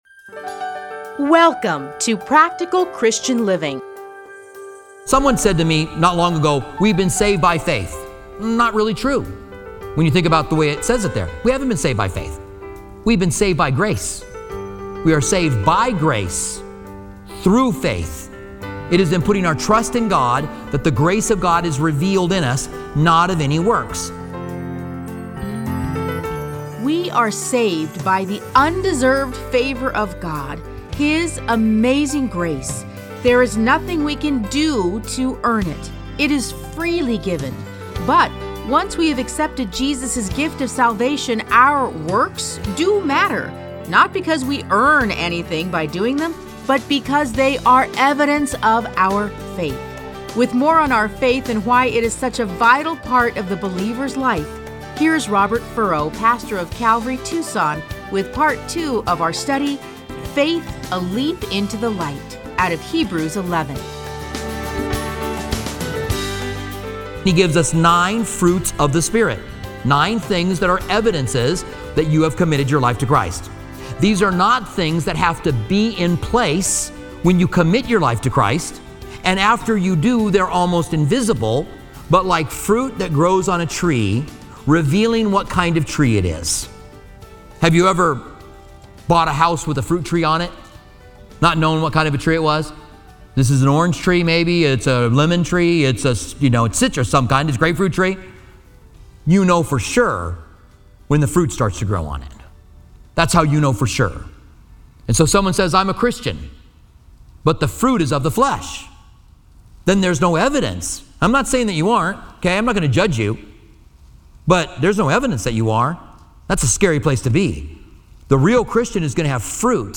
Listen to a teaching from Hebrews 11:1-16.